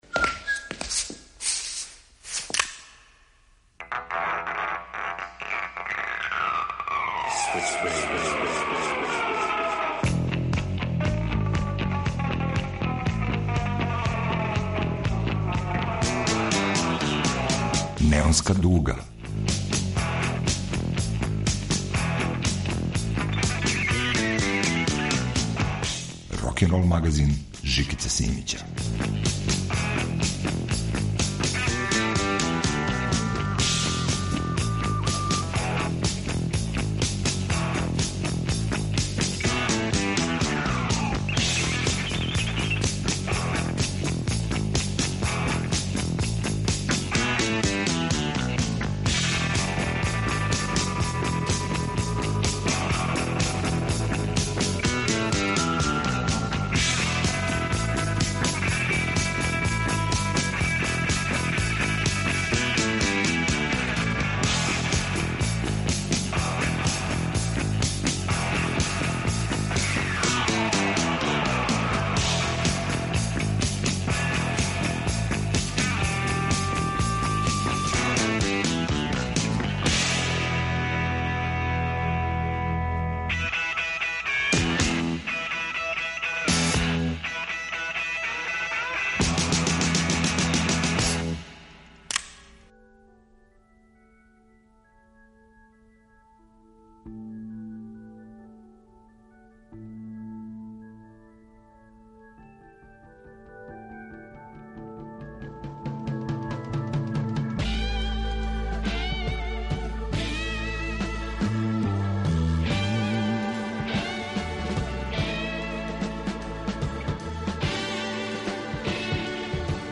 Неонска дуга представља најбоље „обраде” 2021. године.